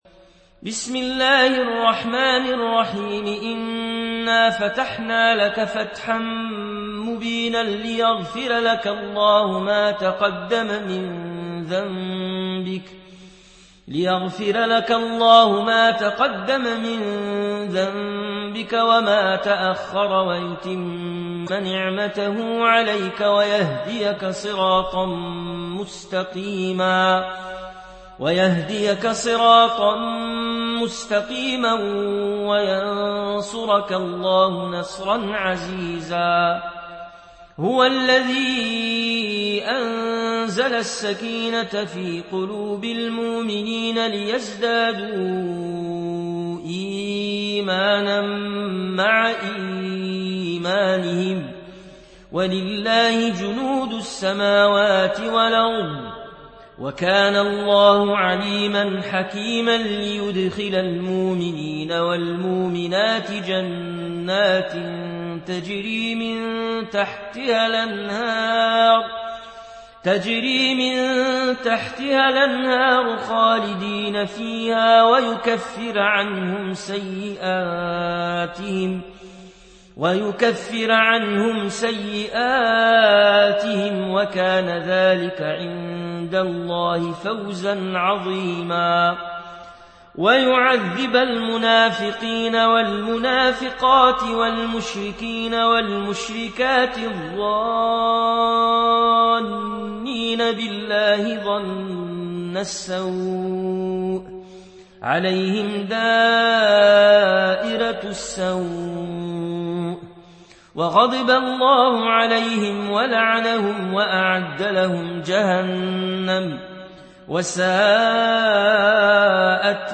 উপন্যাস Warsh